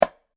Golpe de piedra contra una calabaza
golpe
Sonidos: Acciones humanas